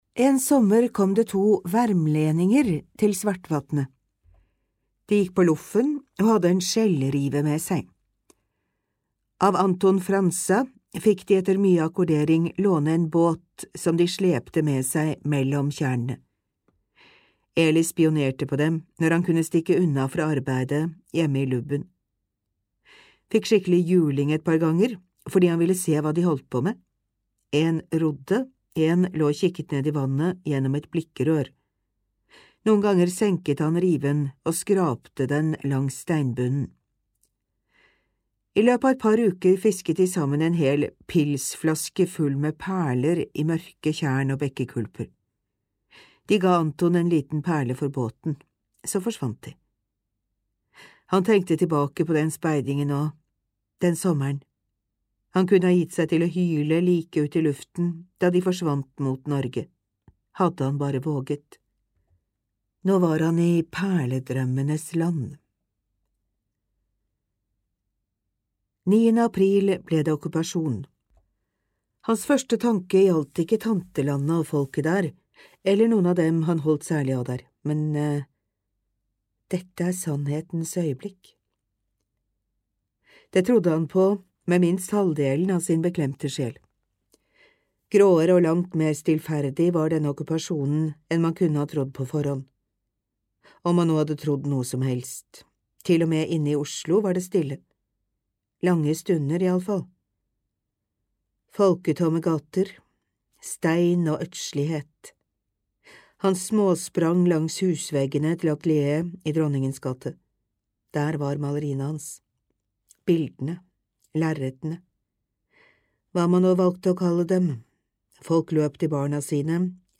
Lydboka ble produsert av Norsk lyd- og blindeskriftbibliotek i 2011.